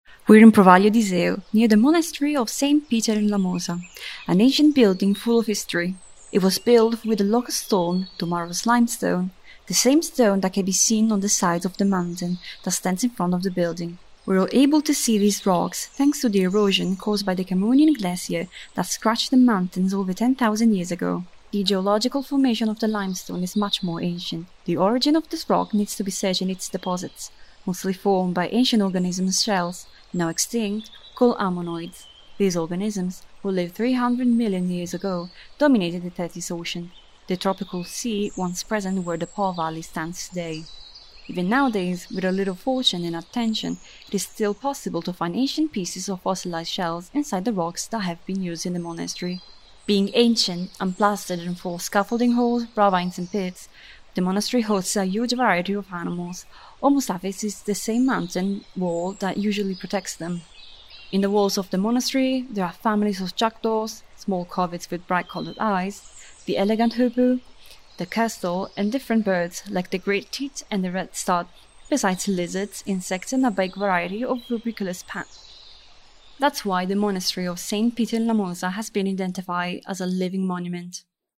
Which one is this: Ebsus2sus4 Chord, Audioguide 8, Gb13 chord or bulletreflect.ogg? Audioguide 8